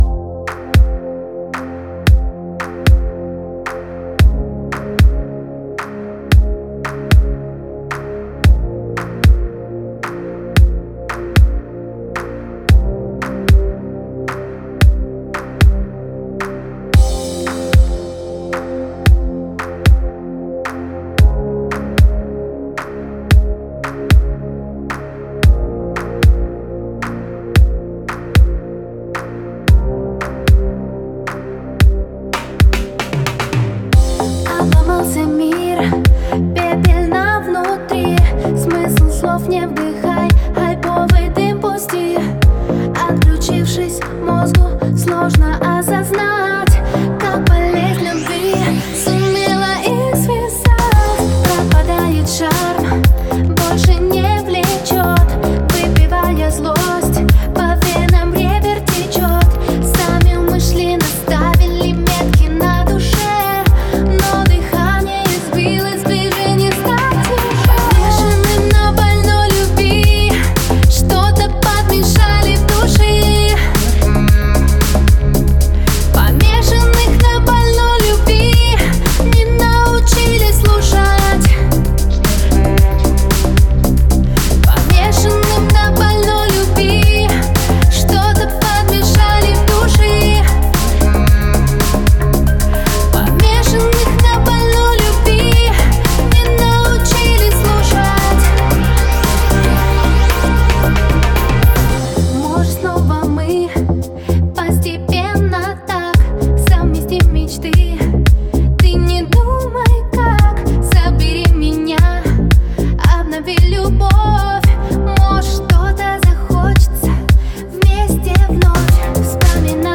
это трек в жанре электронной музыки с элементами хауса